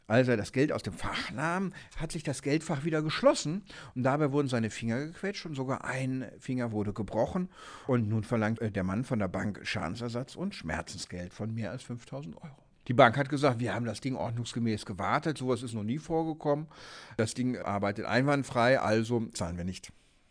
O-Ton + Magazin: Körperverletzung am Geldautomat
O-Töne / Radiobeiträge, , , , , , ,